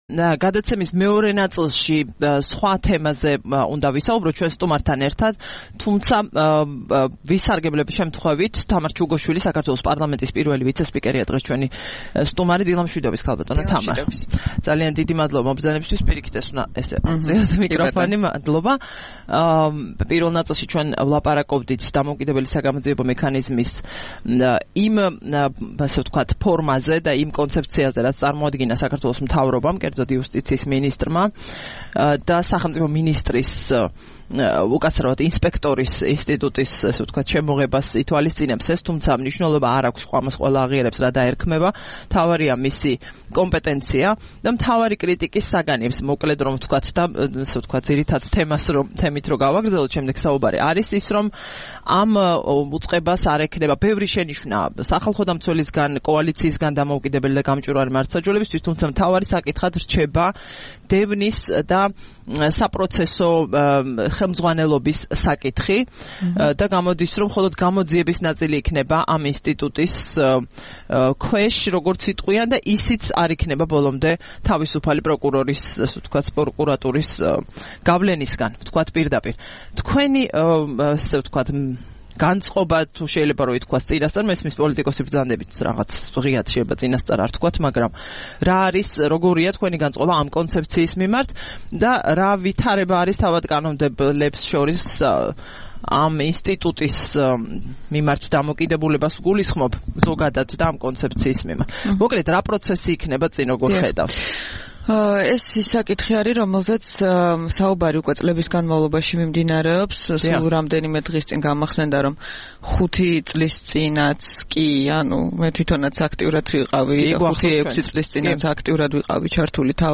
16 თებერვალს რადიო თავისუფლების "დილის საუბრების" სტუმარი იყო თამარ ჩუგოშვილი, საქართველოს პარლამენტის პირველი ვიცესპიკერი.